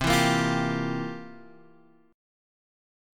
C11 Chord
Listen to C11 strummed